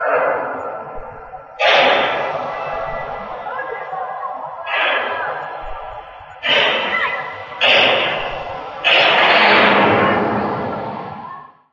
描述：从我住的波罗的海造船厂的窗户听到一些锤击声
Tag: 儿童锤 遥远 船厂 回声 工业 波罗的海 低保 回荡 尖叫 金属 堆场 金属呻吟 金属命中 建筑 呻吟 波罗的海造船厂 命中 噪声 锤击 环境 儿童安全播放 游乐场 金属尖叫